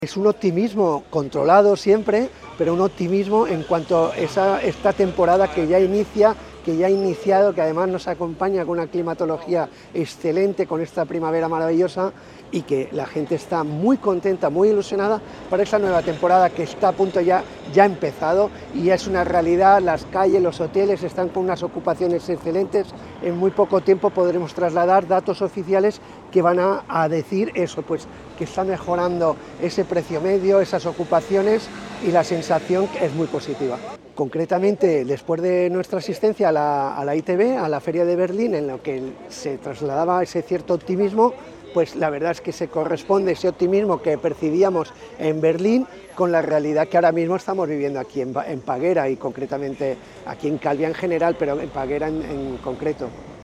declaraciones-alcalde-ocupacion-peguera.mp3